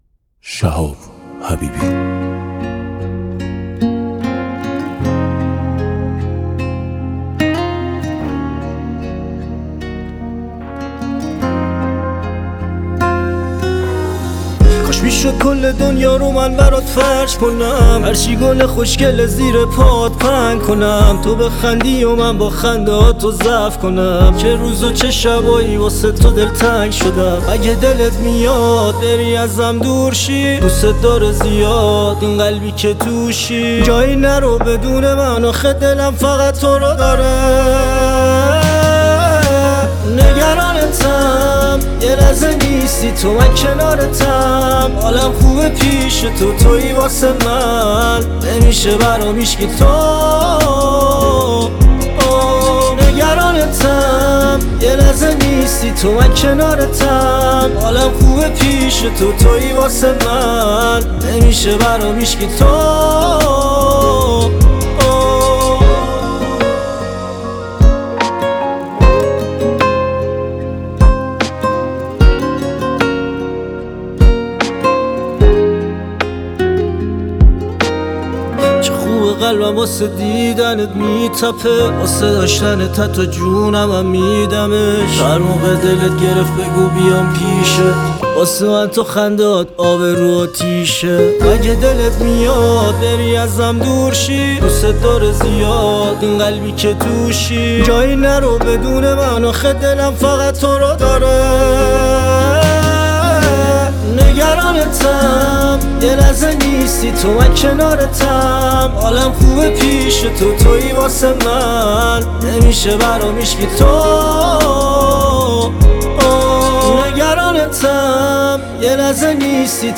این قطعه یک اثر پاپ عاشقانه با تنظیم خلوت است.
گیتار